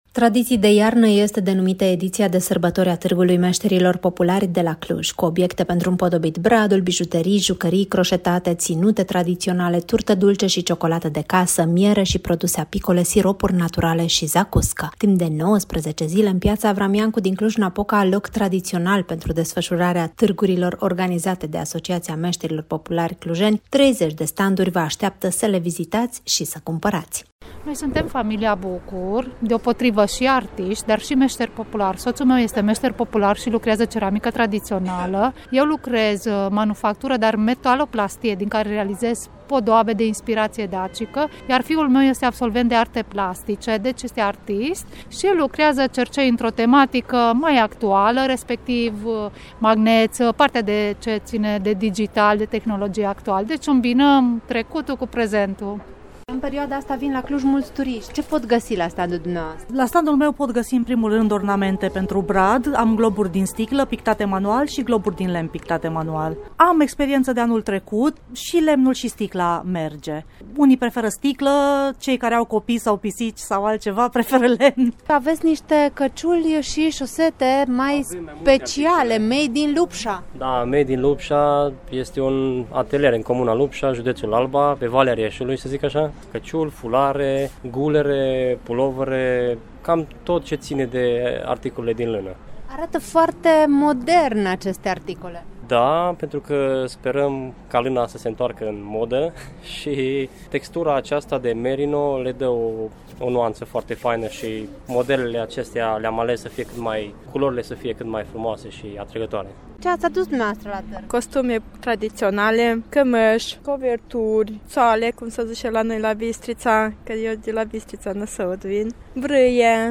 reportaj-targ-de-craciun-la-cluj-1.mp3